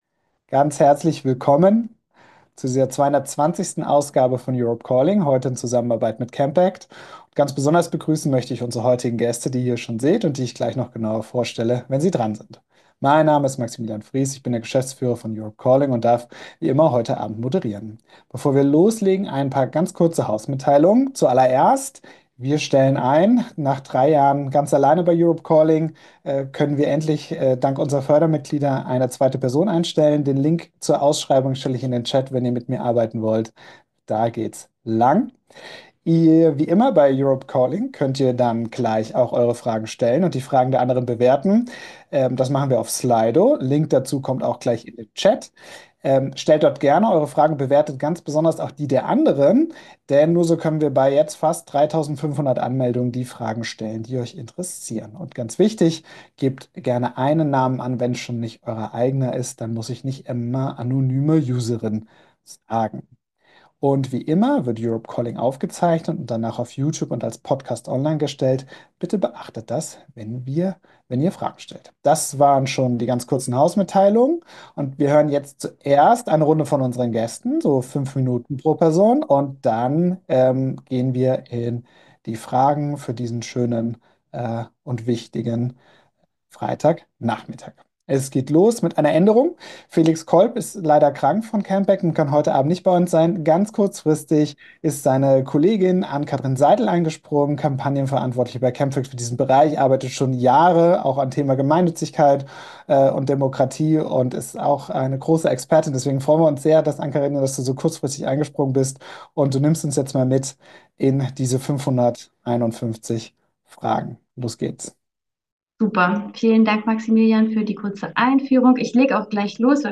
Aufzeichnung der 220. Ausgabe von Europe Calling.
--- Michael Schrodi, SPD-Bundestagsabgeordneter aus Bayern und finanzpolitischer Sprecher der SPD-Bundestagsfraktion